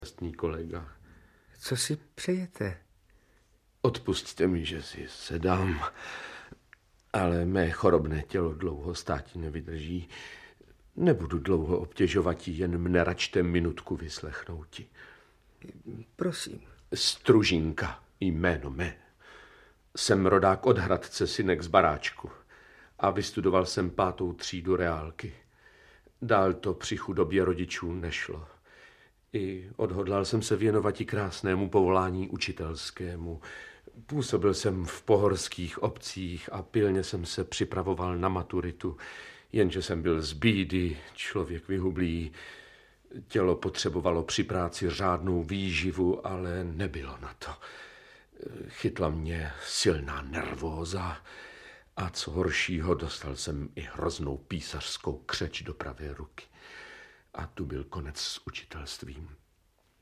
Audiobook
Read: Antonie Hegerlíková